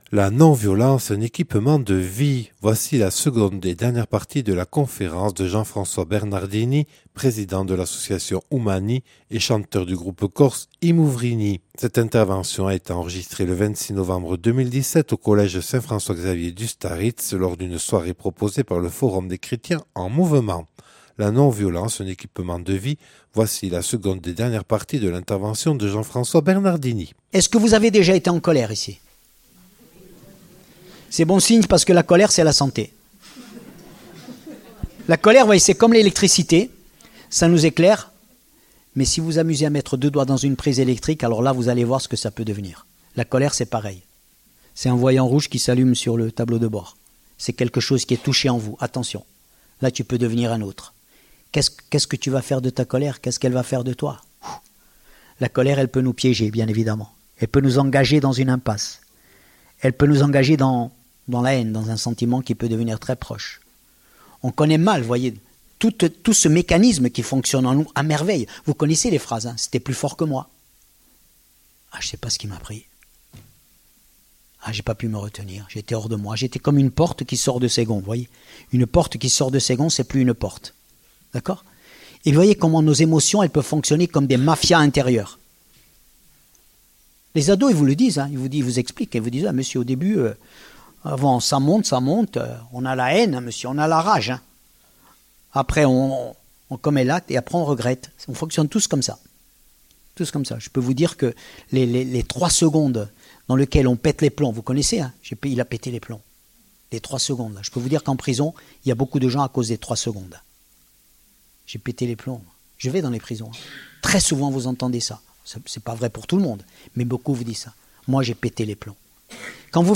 Conférence présentée par Jean-François Bernardini, président de l’association Umani et chanteur du groupe corse I Muvrini. (Enregistrée le 26/11/2017 au collège Saint François-Xavier à Ustaritz).